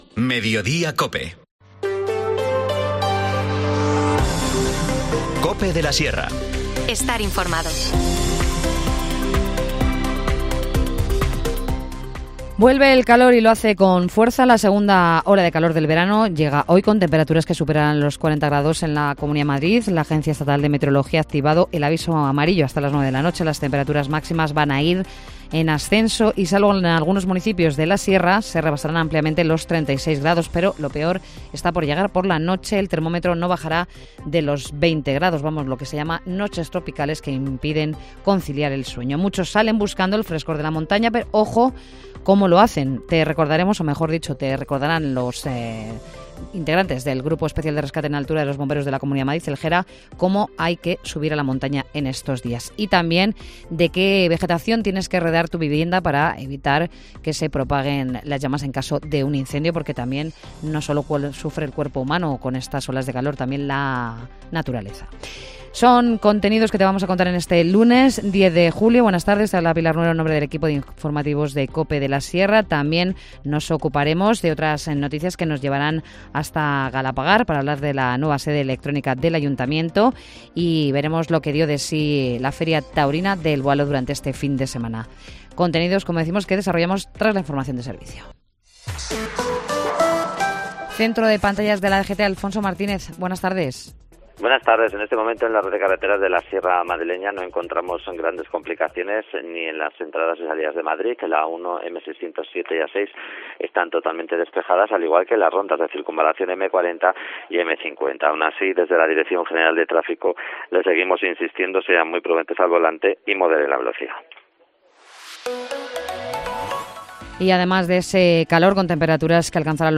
Informativo Mediodía 10 julio